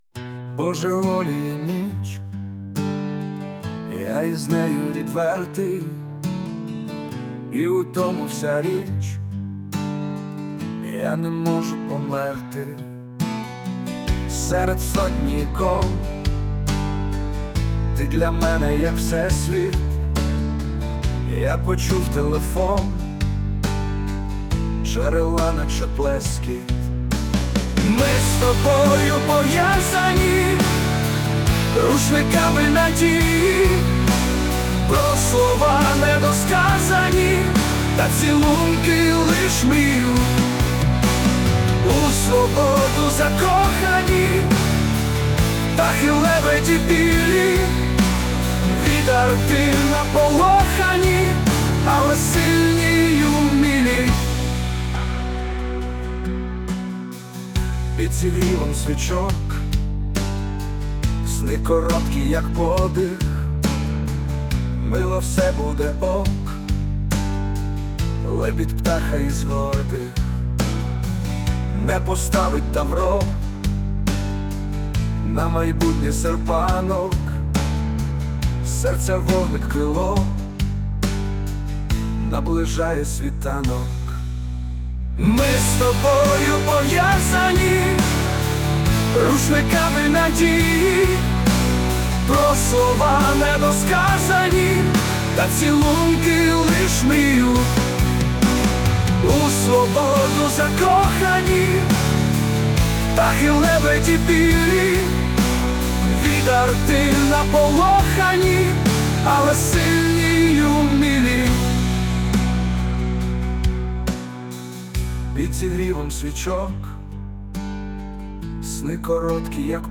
ТИП: Музика
СТИЛЬОВІ ЖАНРИ: Ліричний
ВИД ТВОРУ: Пісня